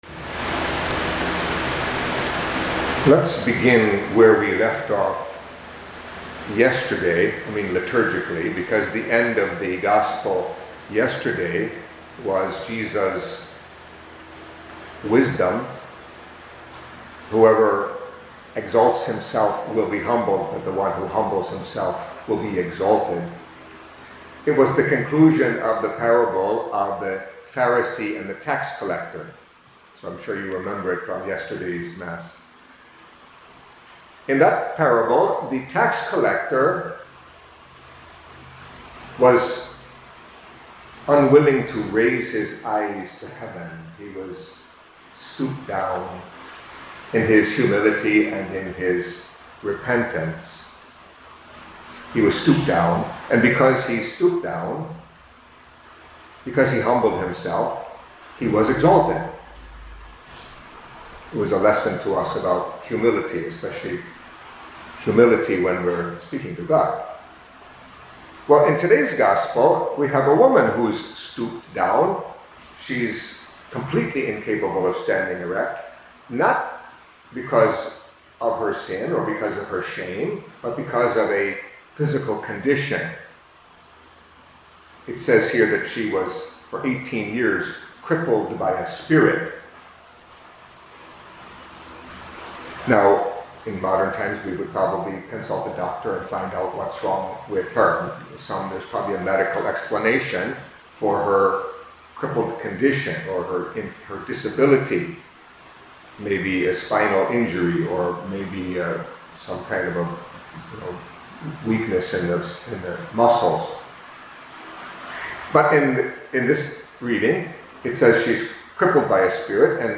Catholic Mass homily for Monday of the Thirtieth Week in Ordinary Time